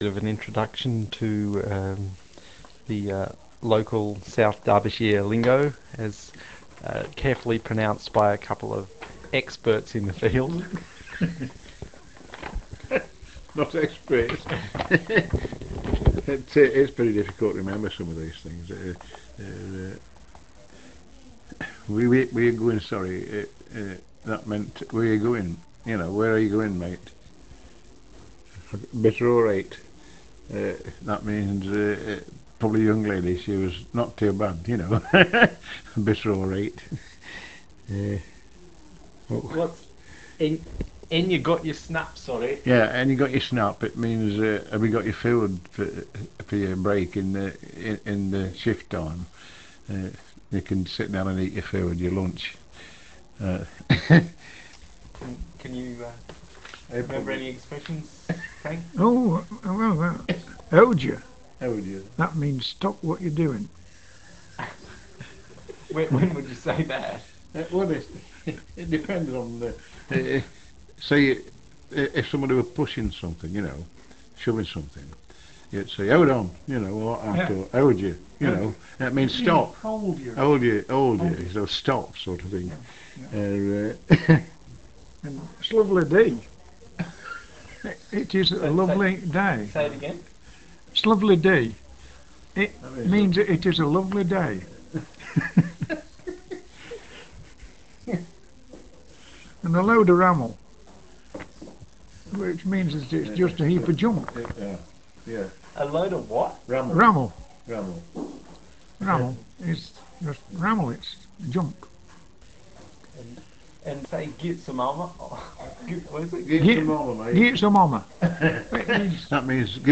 Miners interview
This "interview" was recorded at Swadlincote Library in August 1999 and is of two ex-miners explaining how to "speak Swad":